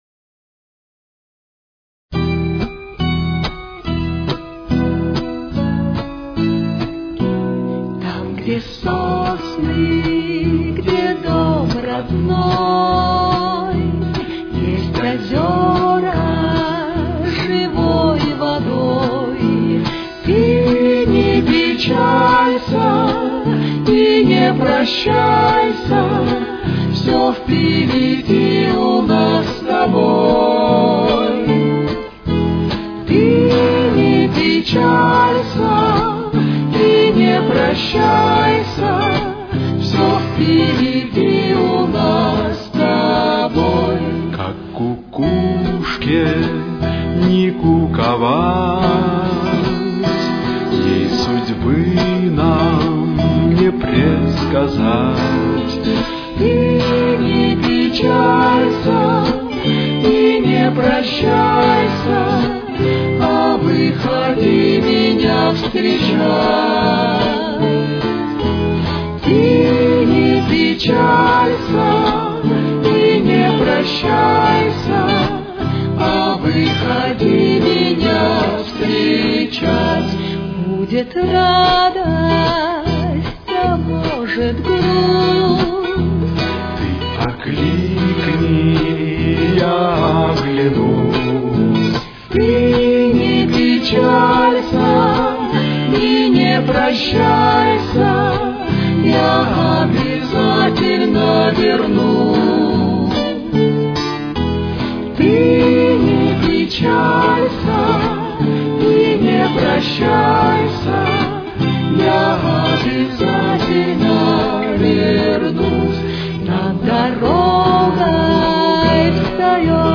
с очень низким качеством (16 – 32 кБит/с)
Ми минор. Темп: 77.